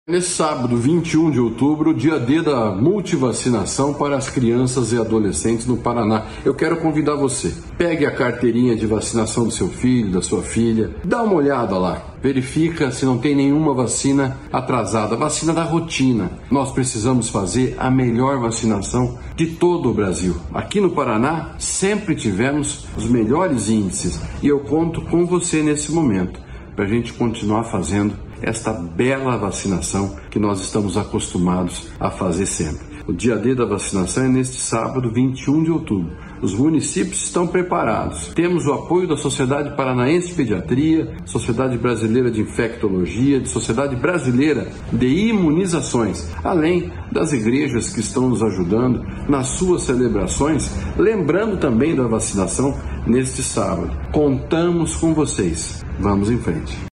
Sonora do secretário da Saúde, Beto Preto, sobre a realização do Dia D estadual de Multivacinação